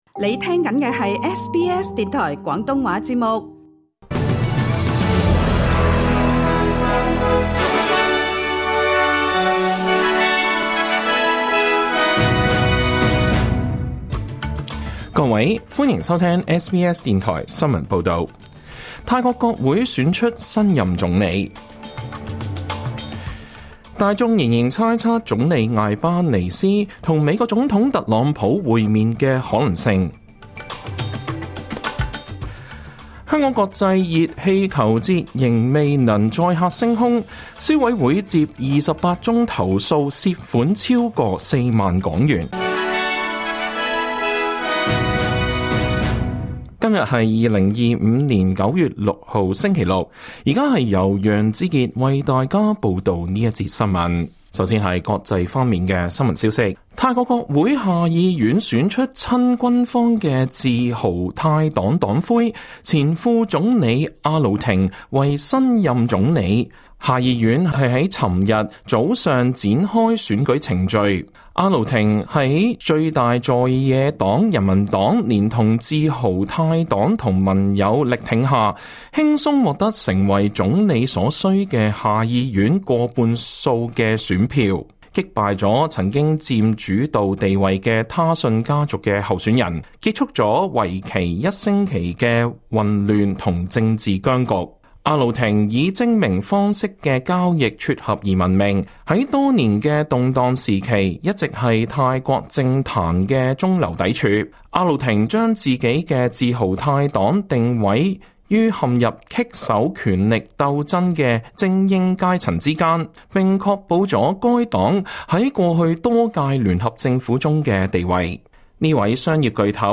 2025 年 9 月 6 日 SBS 廣東話節目詳盡早晨新聞報道。